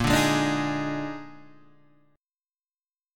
A# 11th